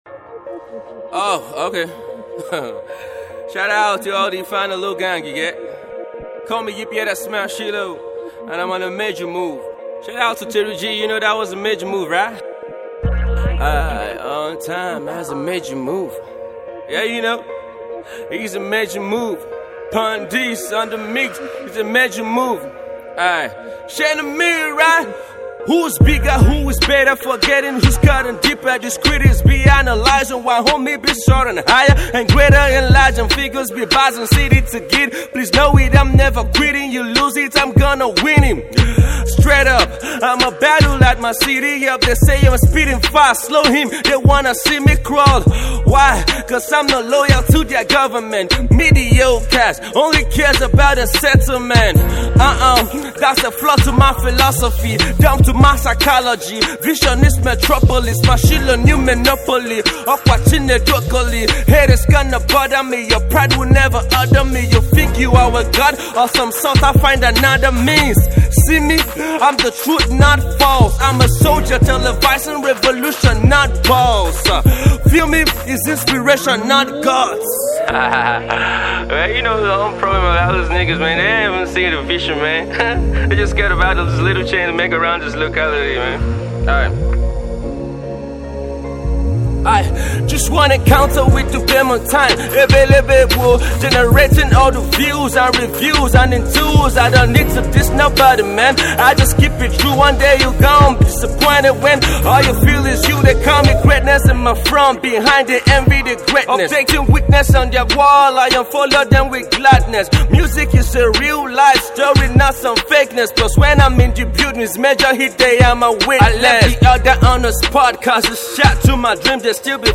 a trap freestyle song